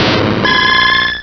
pokeemerald / sound / direct_sound_samples / cries / octillery.aif